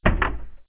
DoorOpen.wav